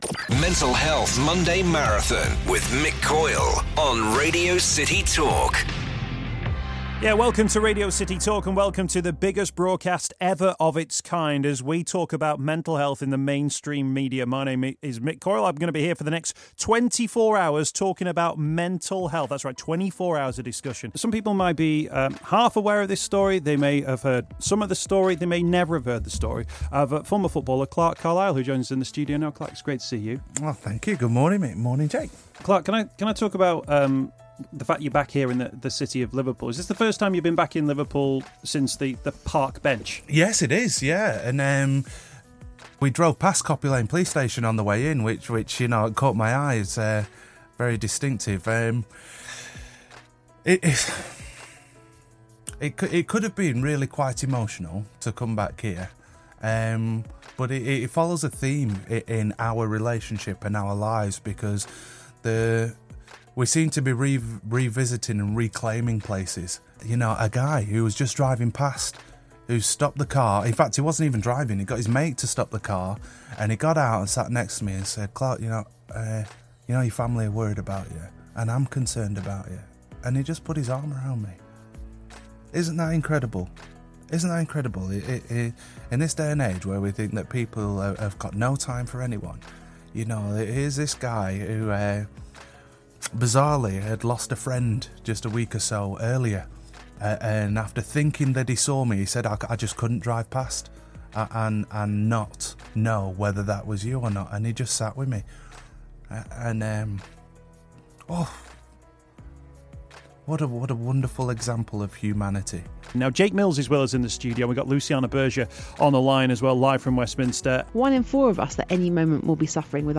A round-up of the highlights from the biggest radio broadcast about mental health